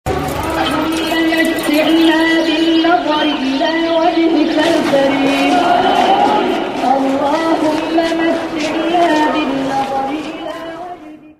Звук голоса муллы, читающего Коран в Марокко